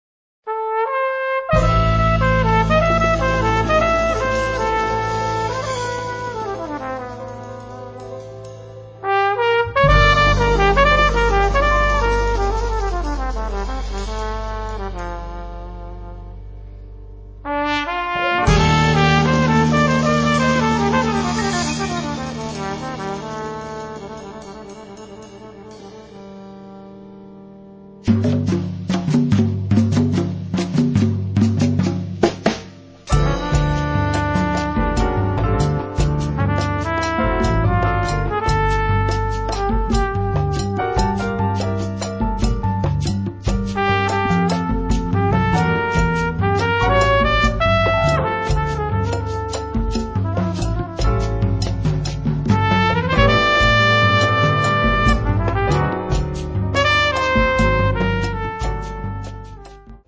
con anima tutta caraibica